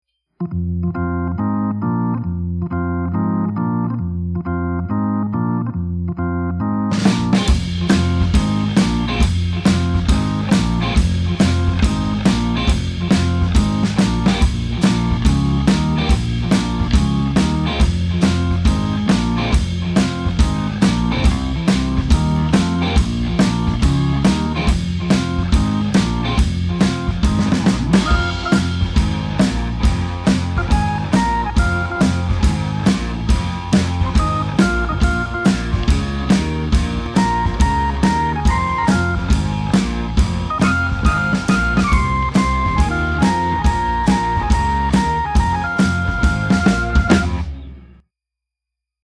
Vi spelar instrumental cool funk i 60/70-tals stil.
Kvartett; elgitarr, elbas, hammond orgel och livetrummor.